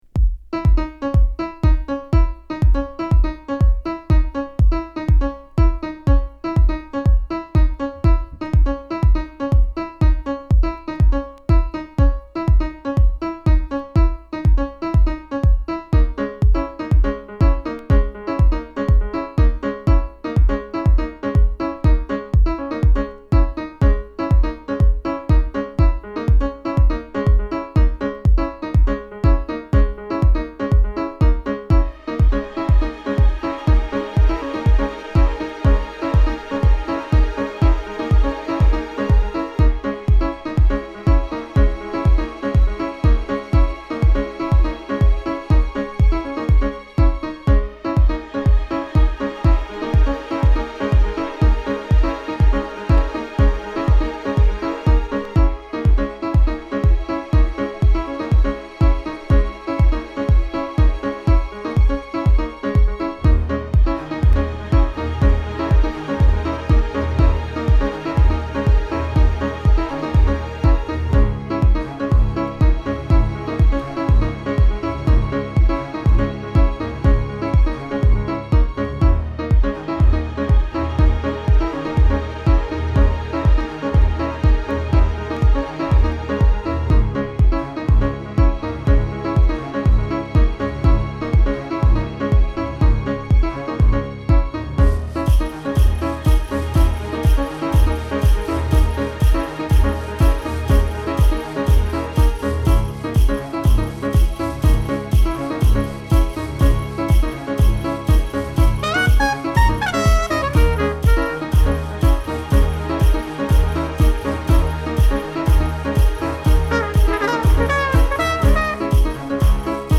原曲のトランペットを活かしつつ、パーカッションやピアノ等の生楽器を要所に加えた最高のジャズ・ハウスを展開！